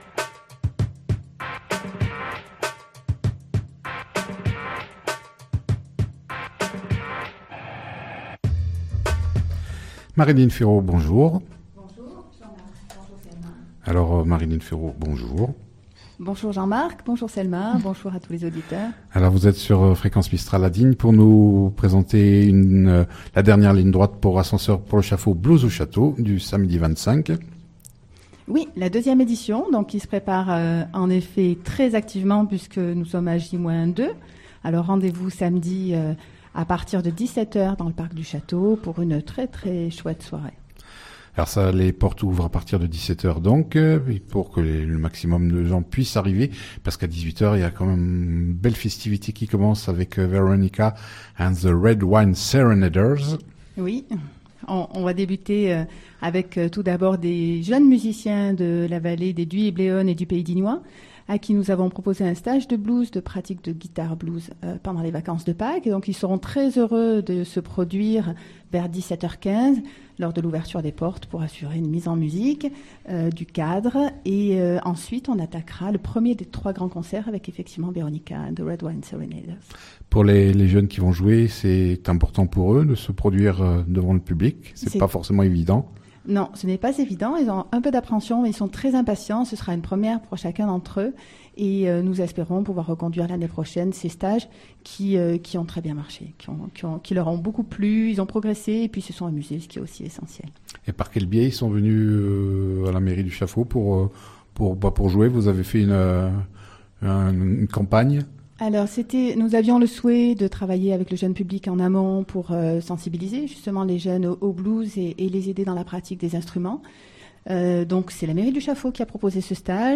Avec appuis musicaux, nous en savons un peu plus sur la belle soirée Blues qui s'annonce samedi 25 juin à partir de 18h.